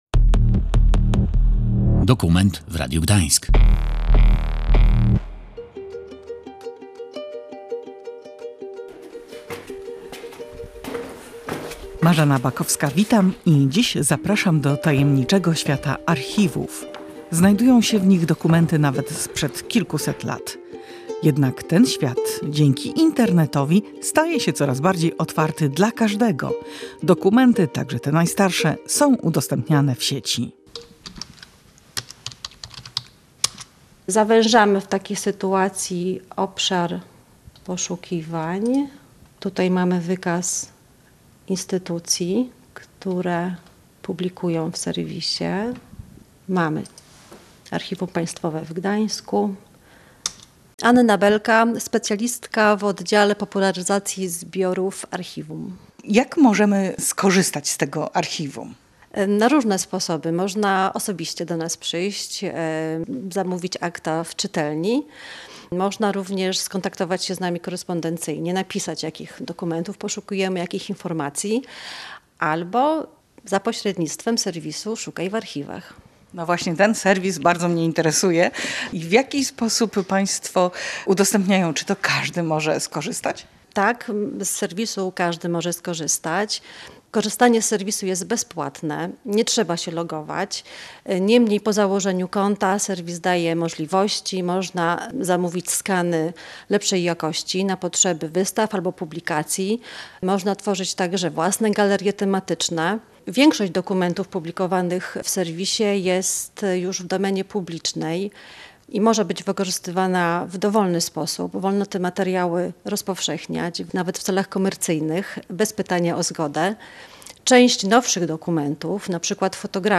z mikrofonem w ręku prześledziła, w jaki sposób dokumenty są przygotowywane do digitalizacji, skanowane i udostępniane online.